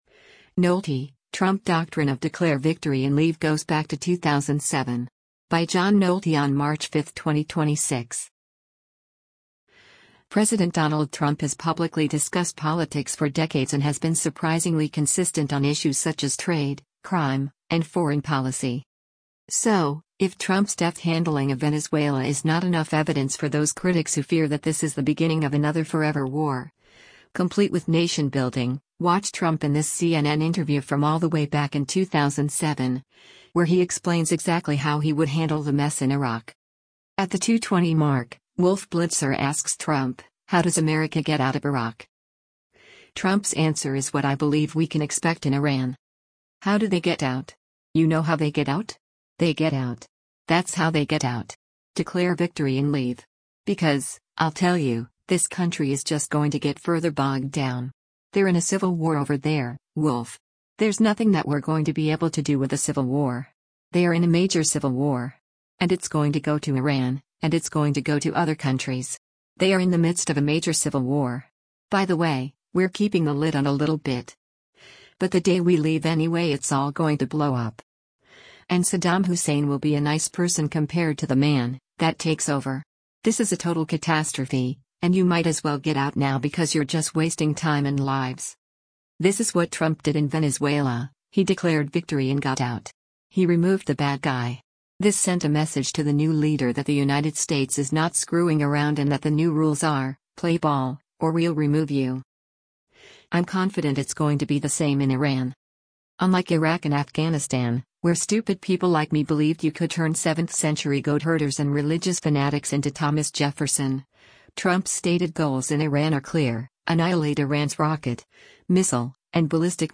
So, if Trump’s deft handling of Venezuela is not enough evidence for those critics who fear that this is the beginning of another forever war, complete with nation building, watch Trump in this CNN interview from all the way back in 2007, where he explains exactly how he would handle the mess in Iraq:
At the 2:20 mark, Wolf Blitzer asks Trump, How does America get out of Iraq?